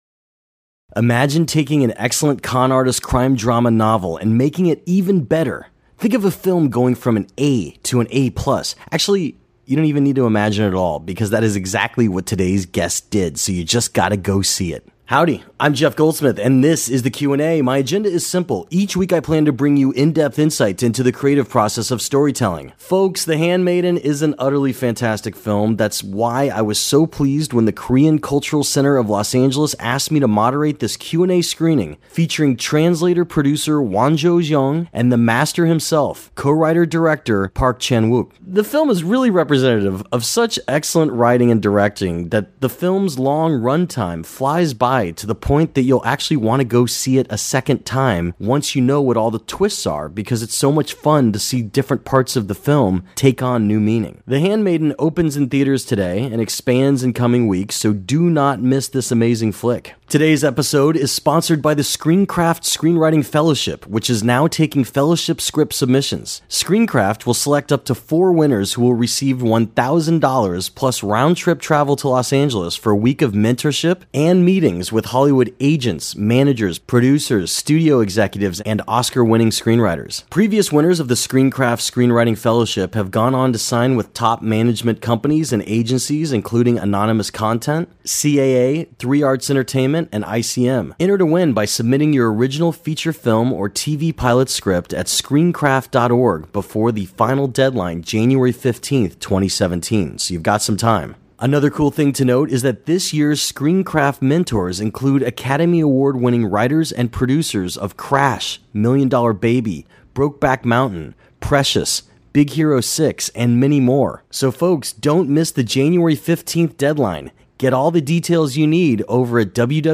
ParkChanwookTheHandmaidenQandA.mp3